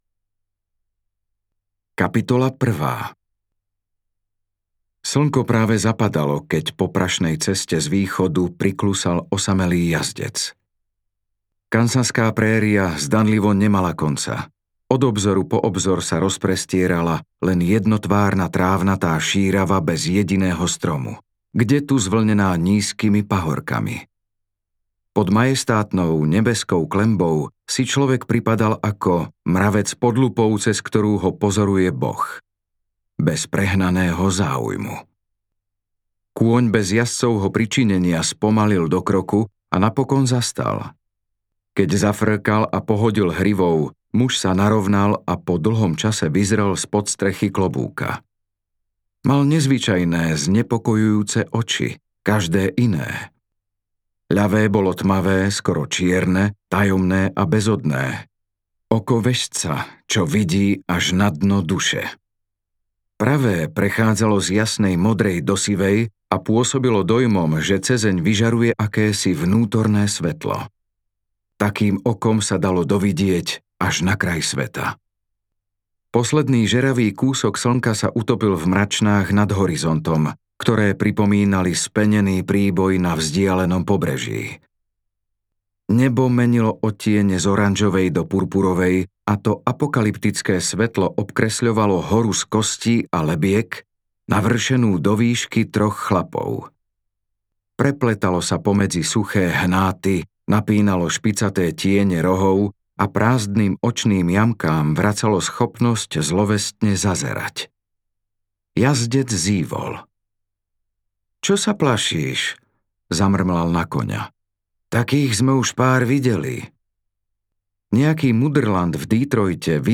Zakliaty kláštor audiokniha
Ukázka z knihy